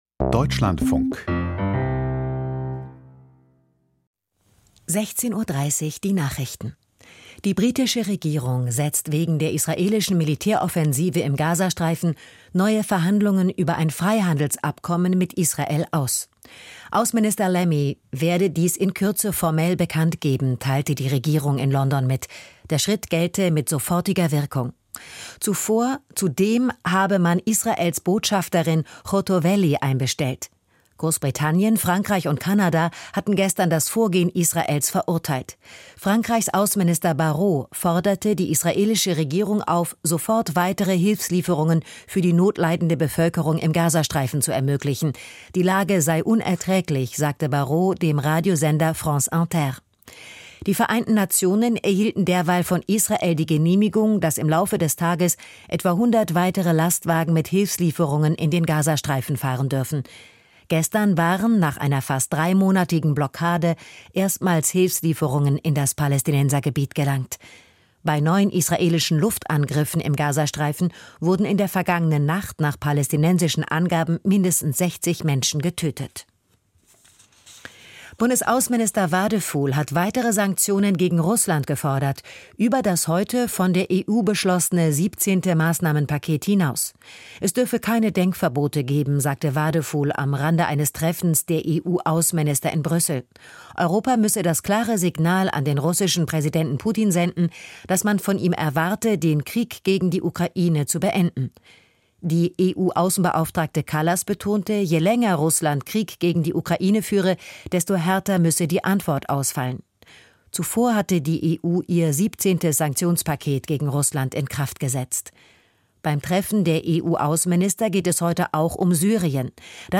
Die Nachrichten vom 20.05.2025, 16:30 Uhr